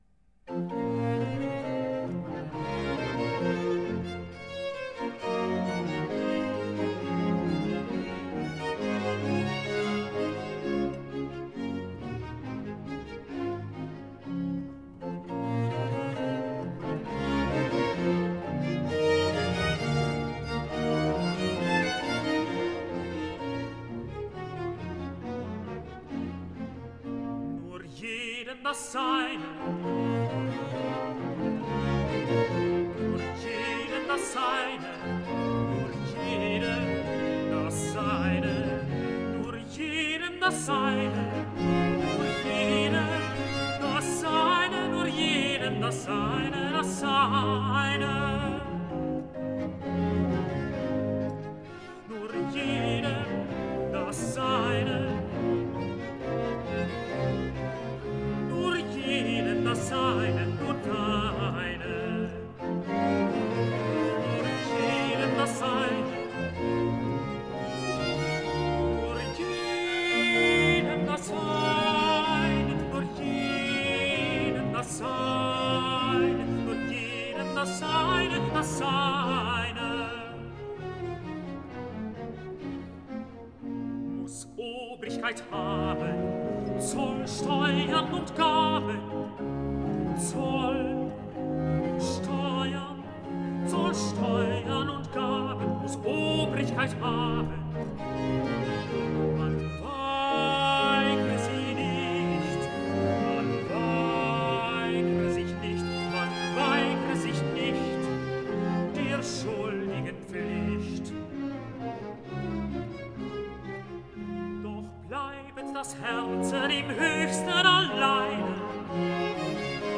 Aria - Tenore